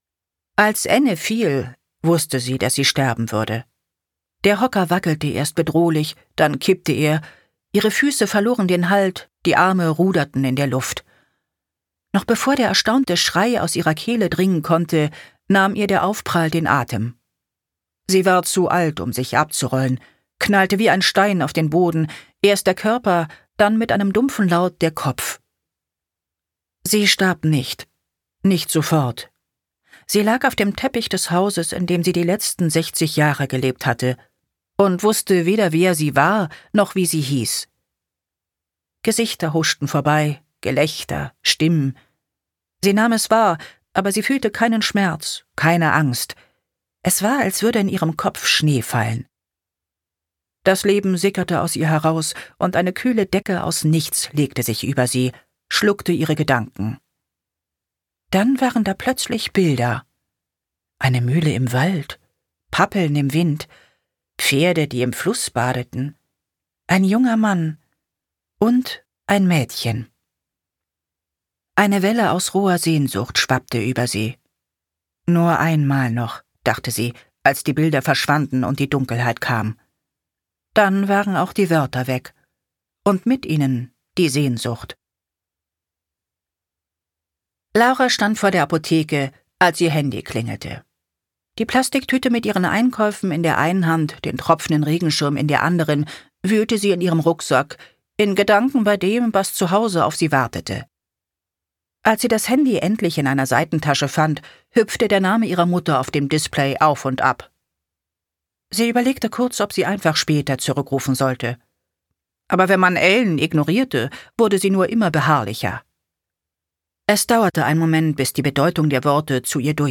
Emotional, modern, generationsübergreifend: ein Hörbuch, das zum Nachdenken und zum Gespräch anregt.
Gekürzt Autorisierte, d.h. von Autor:innen und / oder Verlagen freigegebene, bearbeitete Fassung.